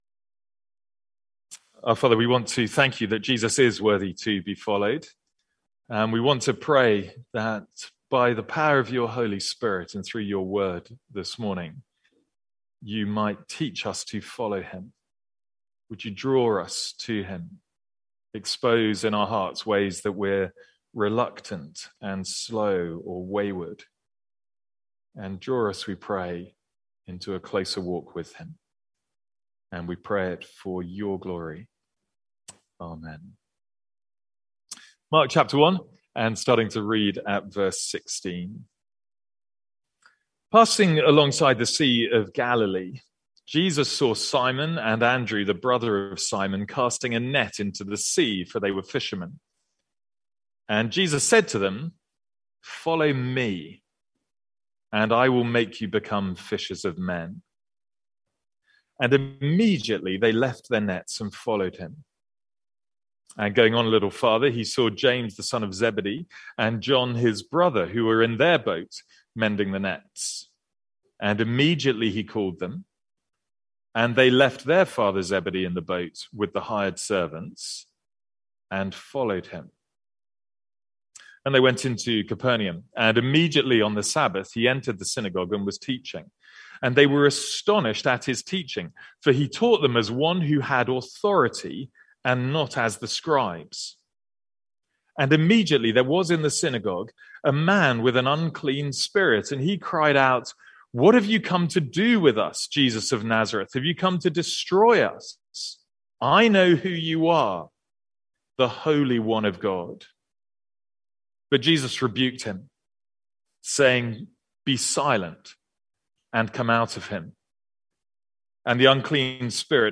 From our morning series in the Gospel of Mark.